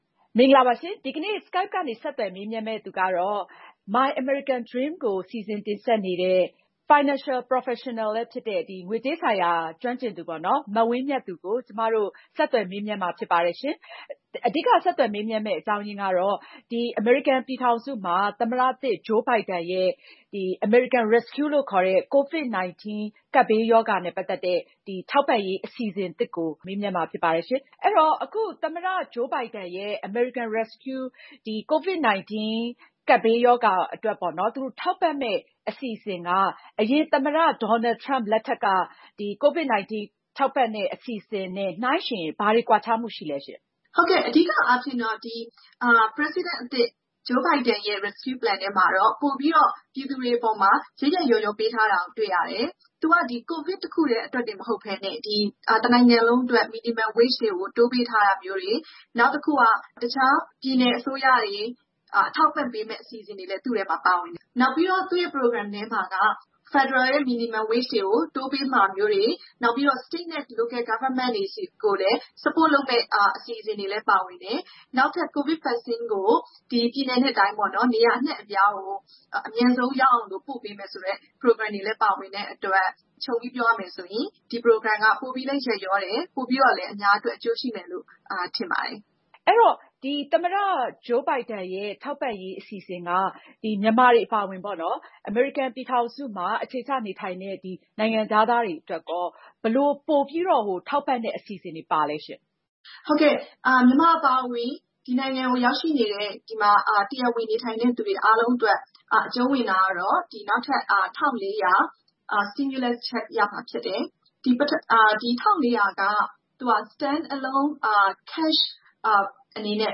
Skype ကနေ ဆက်သွယ်မေးမြန်းထားပါတယ်။